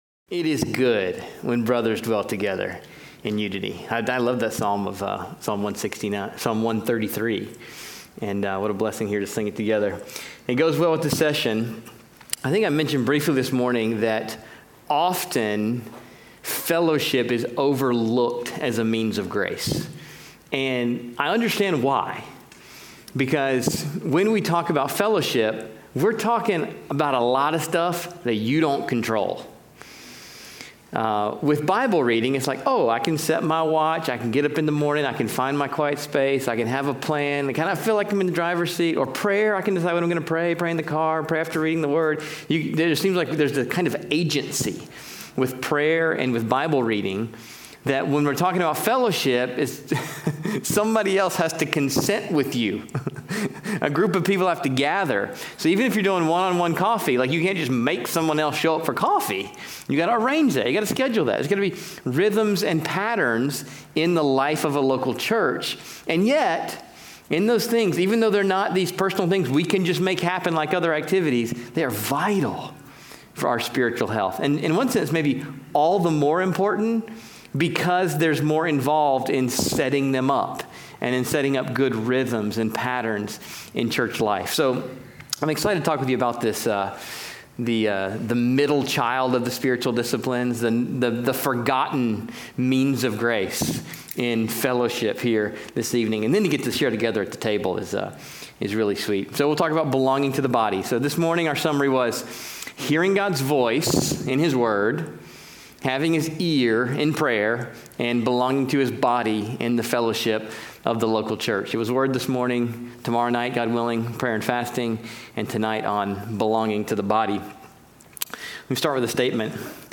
Eden Baptist Bible Conference | Minneapolis